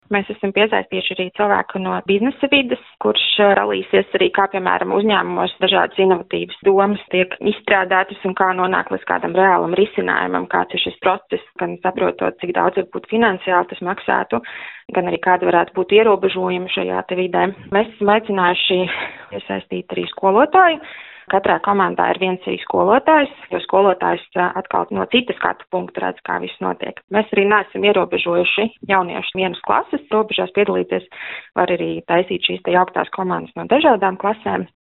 " intervijā Skonto mediju grupai